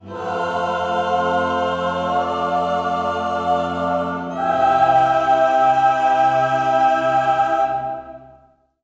Church Choir singing Amen. 257 の再生回数です。